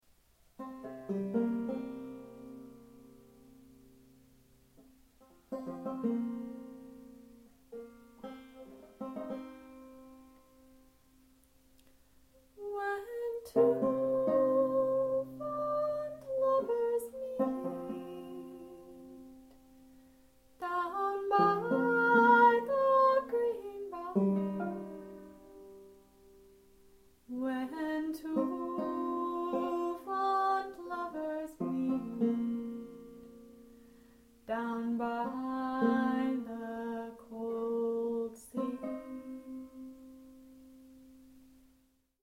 original tunes,